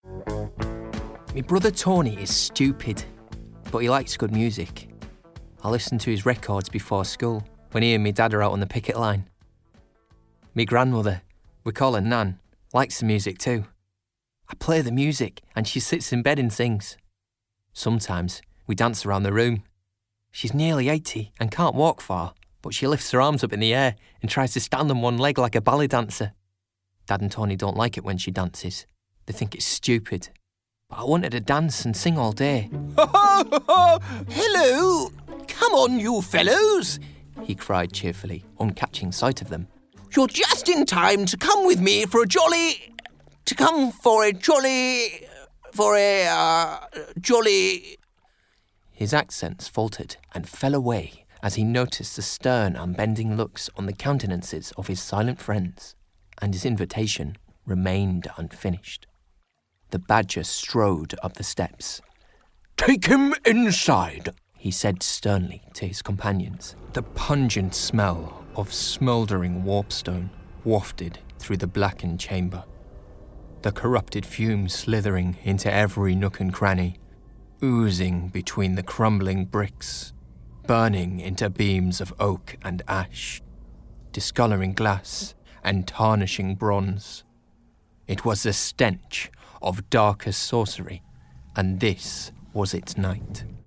Audiobook Reel
• Native Accent: Teeside
• Home Studio
His native north-east is light and amicable, but he can plunge those vocal depths for villainy at a finger click.